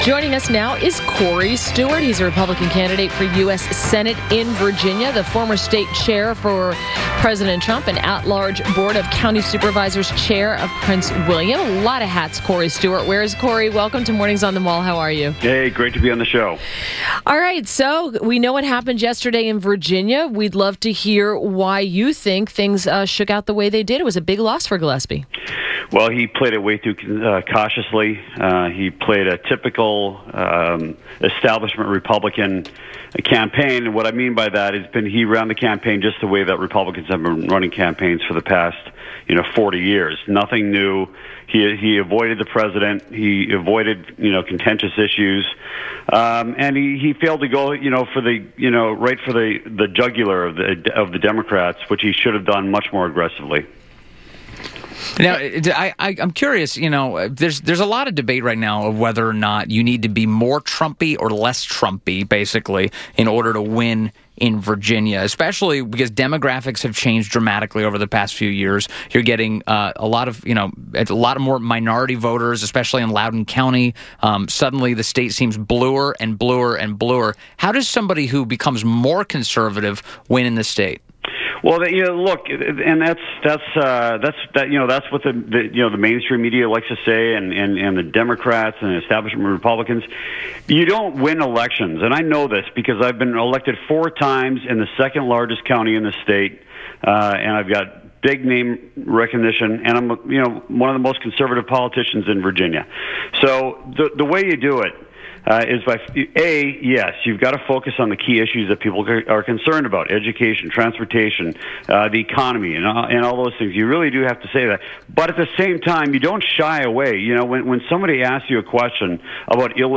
WMAL Interview - COREY STEWART - 11.09.17
INTERVIEW -- COREY STEWART -- is a Republican Candidate for U.S. Senate in Virginia, Former State Chairman for Donald Trump, and At-Large Board of County Supervisors Chairman of Prince William.